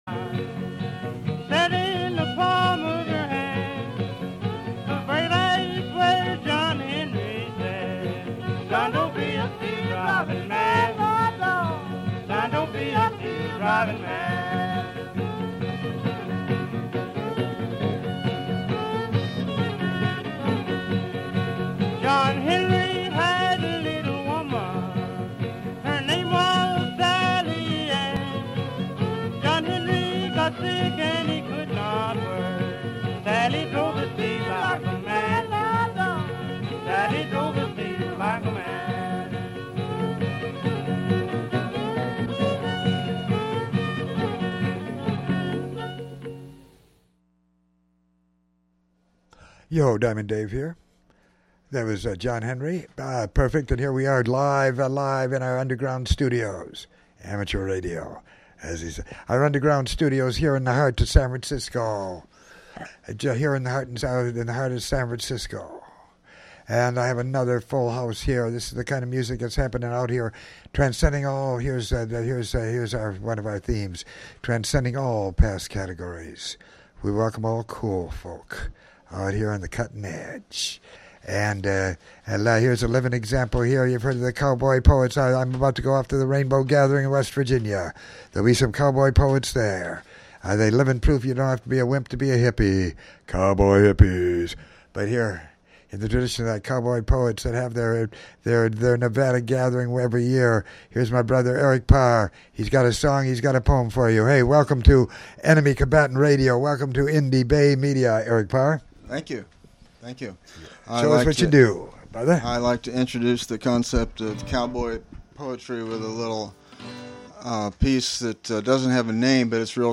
Third Show of poetry and music
Cowboy Poet